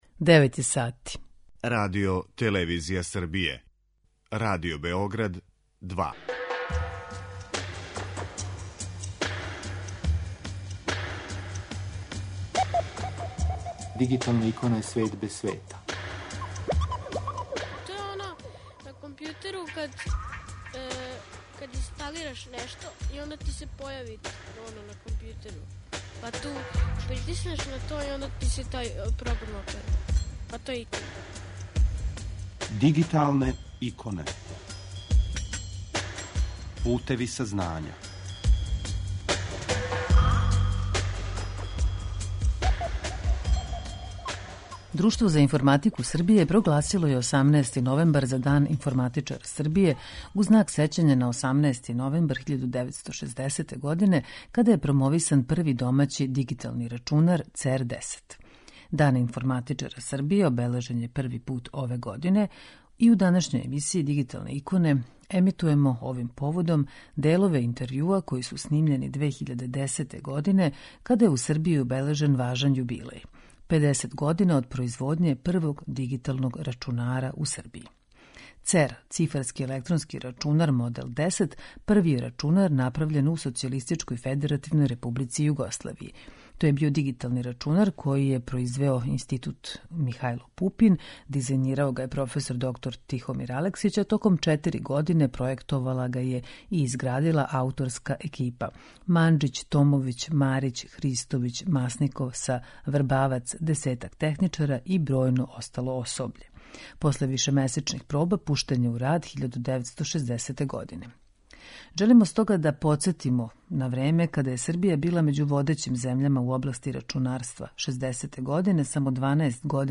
У данашњој емисији Дигиталне иконе емитујемо делове одабраних интервјуа који су снимљени 2010. године када је у Србији обележен важан јубилеј: педесет година од производње првог дигиталног рачунара.